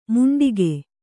♪ muṇḍige